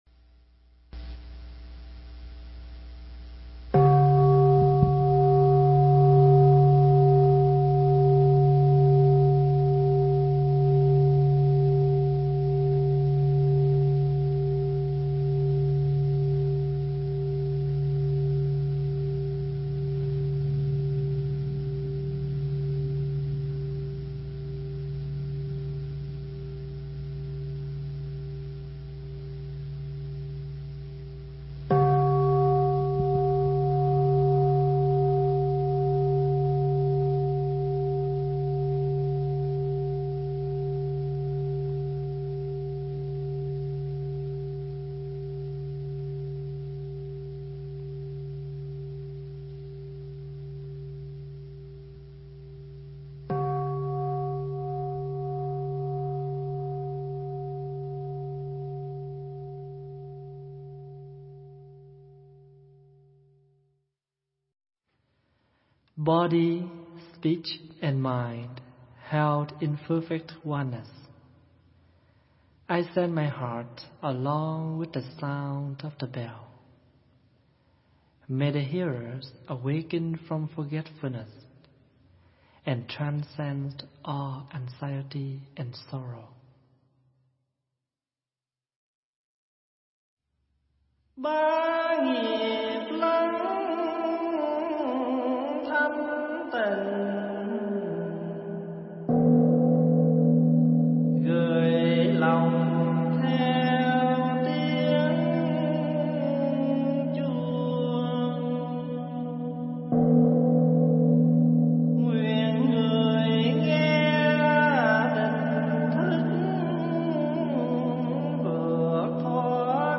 Tải mp3 thuyết pháp Rửa Tội
thuyết giảng tại Tu Viện Trúc Lâm, Canada